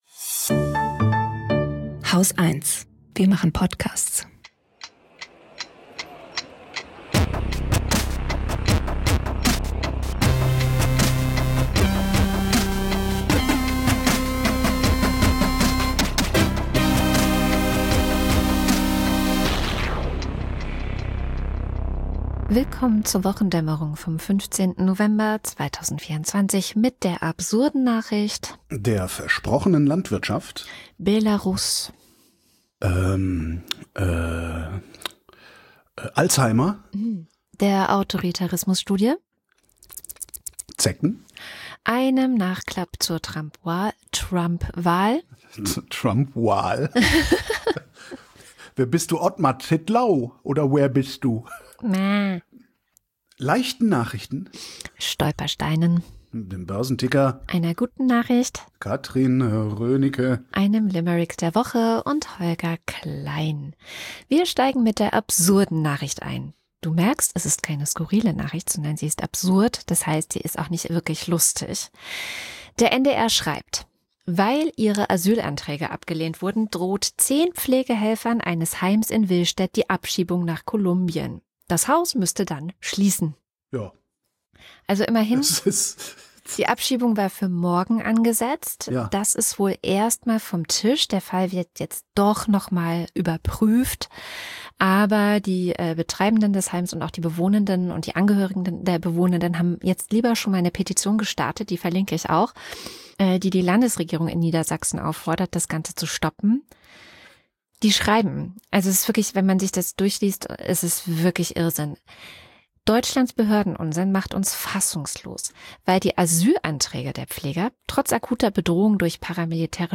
Gesprächspodcast
News Talk